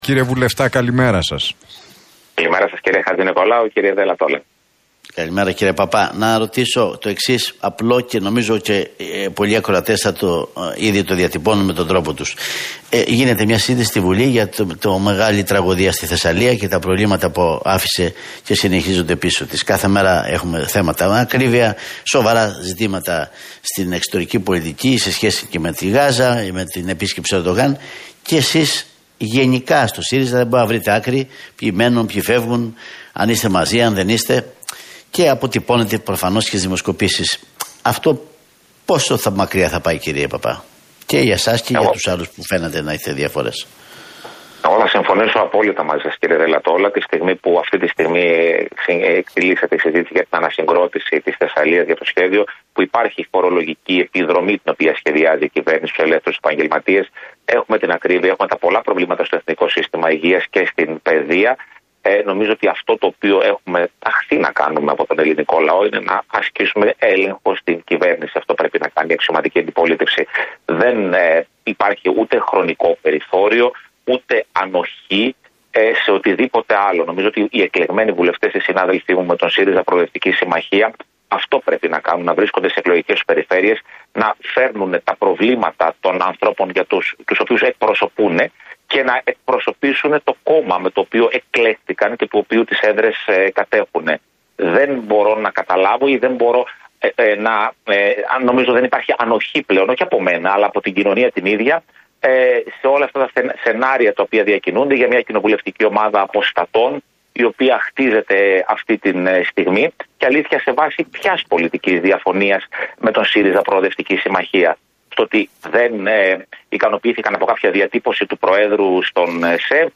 Πέτρος Παππάς στον Realfm 97,8: Δεν υπάρχει ανοχή στα σενάρια για κοινοβουλευτική ομάδα αποστατών